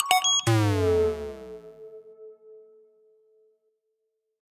SFX_Lose.ogg